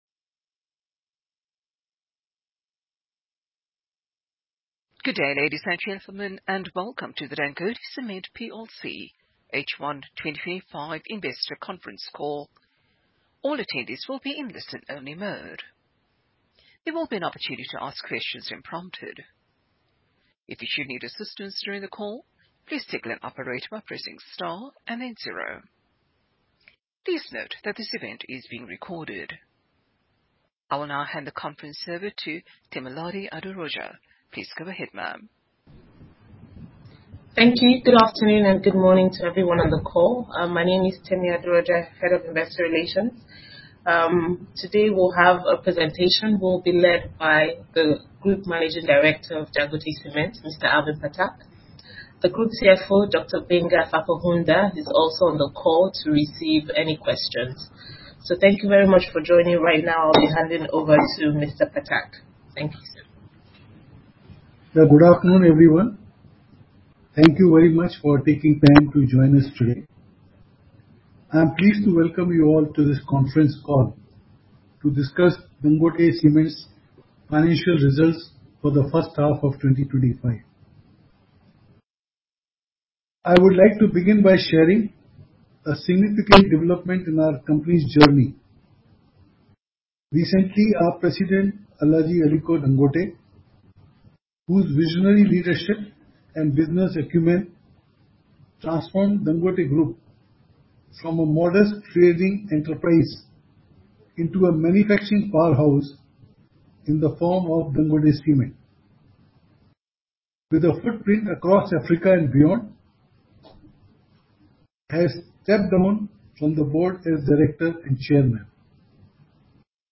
Dangote-Cement-Plc-H1-2025-Investor-Conference-call.mp3